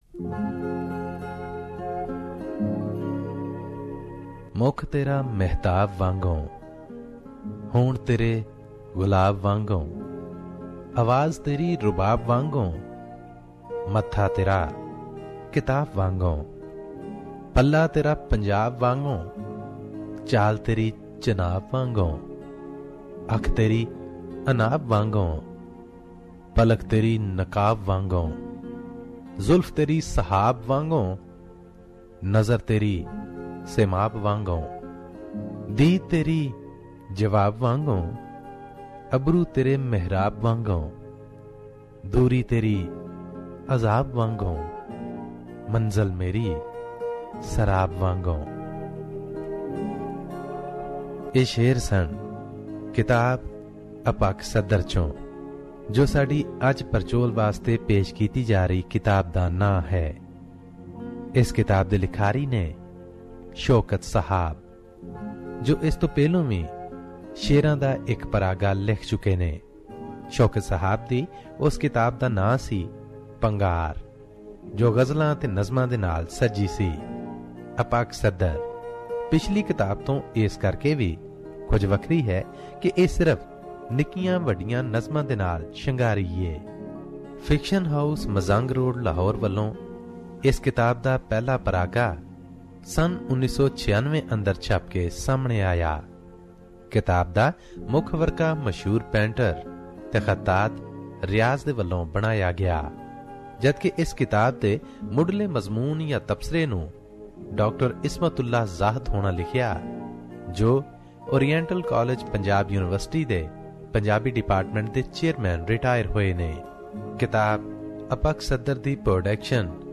Another quality book review - Apak Sadhar wirtten by Shokat Sahaab